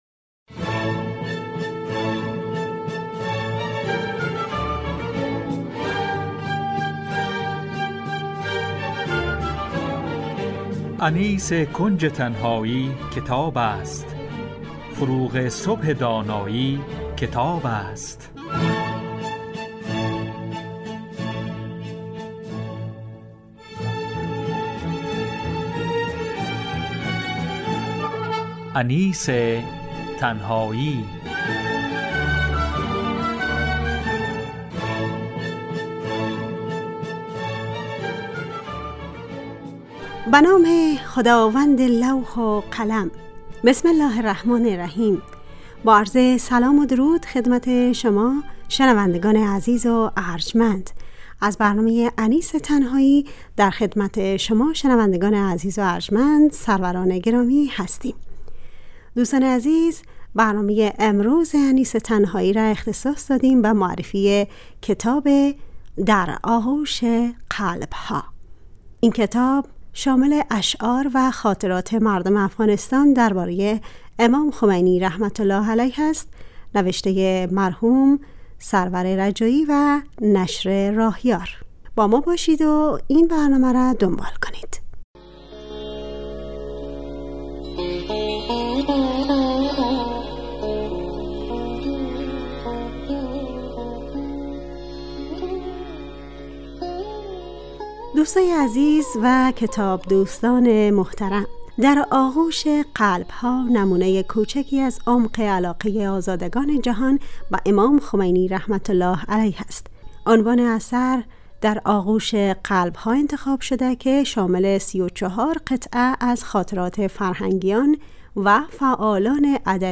معرفی کتاب
همراه با گزیده ای از سطرهایی از کتاب